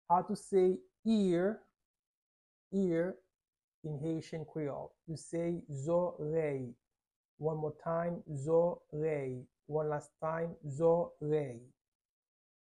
How to say "Ear" in Haitian Creole - "Zòrèy" pronunciation by a native Haitian teacher
“Zòrèy” Pronunciation in Haitian Creole by a native Haitian can be heard in the audio here or in the video below:
How-to-say-Ear-in-Haitian-Creole-Zorey-pronunciation-by-a-native-Haitian-teacher.mp3